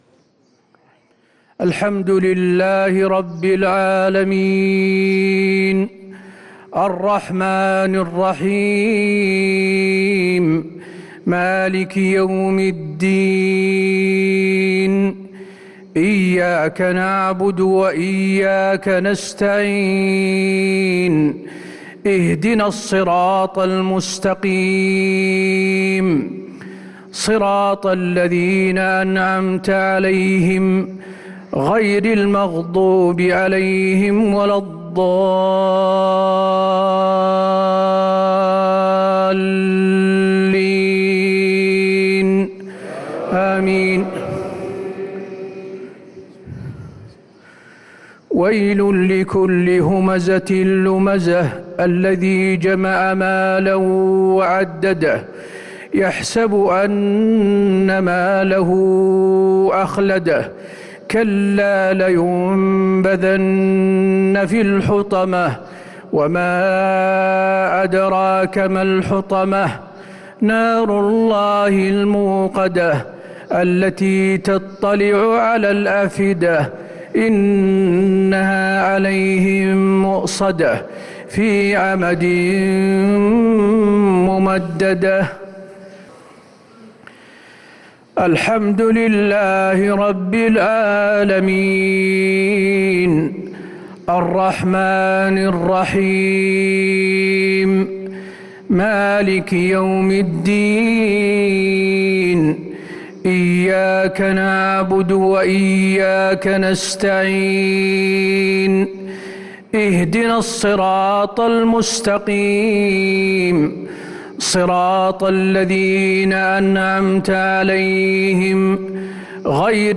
صلاة الجمعة 6 صفر 1444هـ سورتي الهمزة و الإخلاص | Jumu'ah prayer from Surah Al-Humaza and Al-Ikhlaas 2-9-2022 > 1444 🕌 > الفروض - تلاوات الحرمين